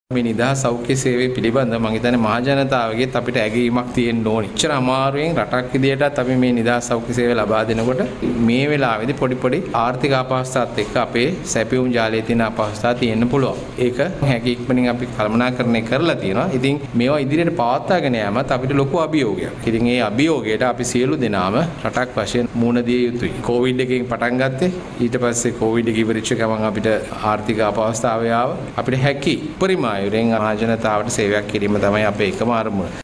සෞඛ්‍ය සේවා අධ්‍යක්ෂ ජනරාල්ගෙන් ප්‍රකාශයක්
සෞඛ්‍ය ක්ෂේත්‍රයට අදාළ සොයා ගැනීම් හා පර්යේෂණ ප්‍රතිඵල ජනගත කිරීම වෙනුවෙන් පවුල් සෞඛ්‍ය කාර්යාංශ ශ්‍රවනාගාරයේ පැවැත්වුනු වැඩසටහනට එක්වෙමින් ඒ මහතා මේ බව ප්‍රකාශ කළා.